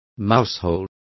Complete with pronunciation of the translation of mousehole.